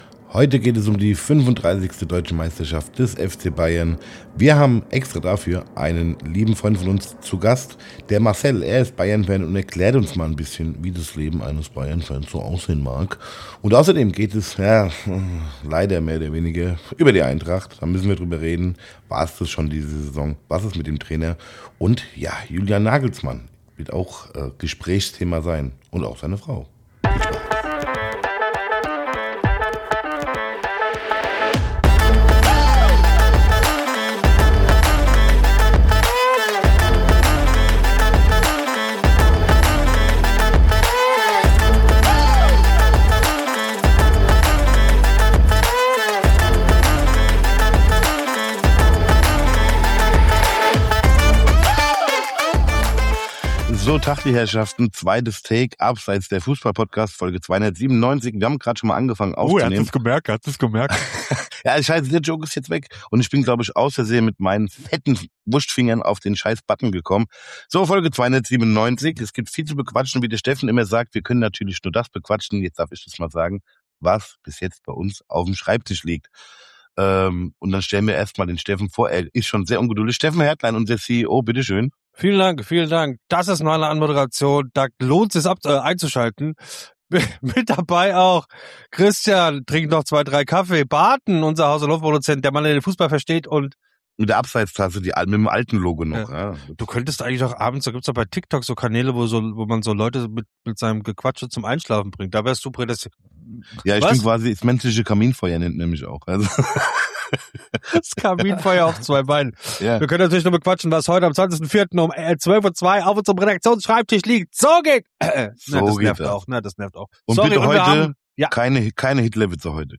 Beschreibung vor 2 Tagen Die Bayern sind zum 35. Mal Meister, und wir haben einen Bayern-Fan zu Gast, mit dem wir darüber reden.